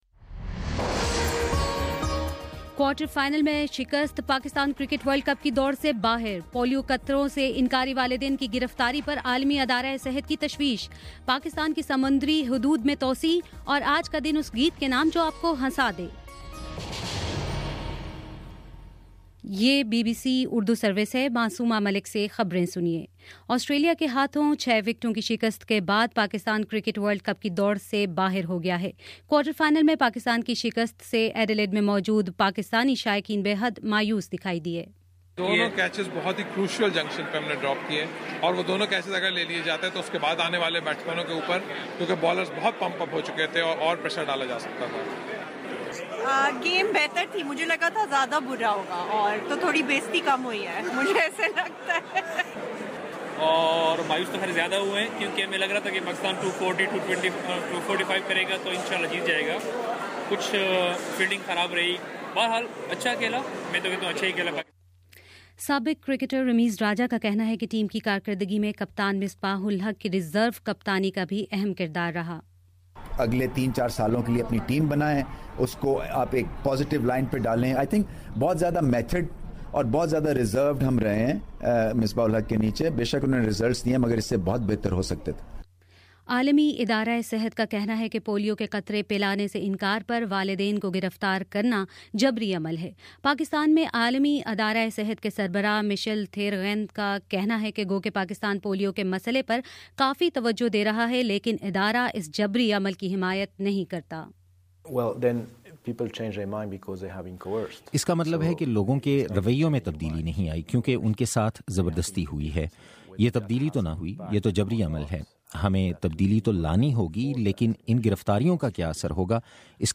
مارچ 20: شام پانچ بجے کا نیوز بُلیٹن